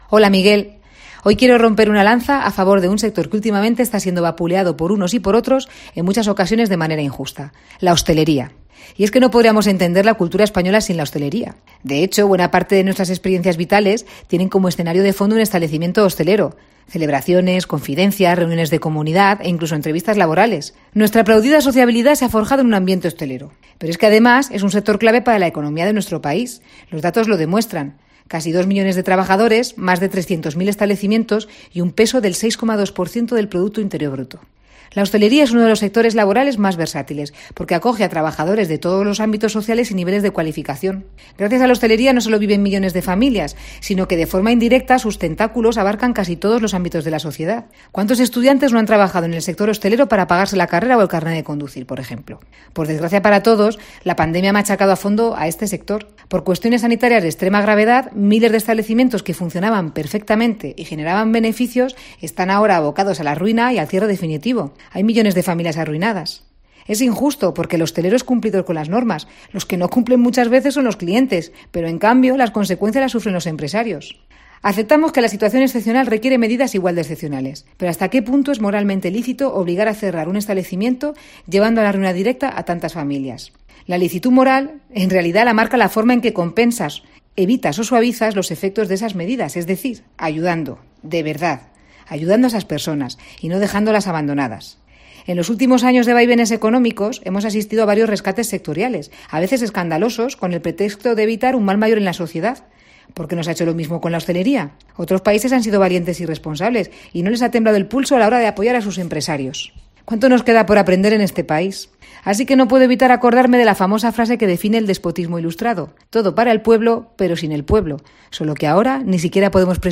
columna radiofónica